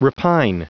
Prononciation du mot repine en anglais (fichier audio)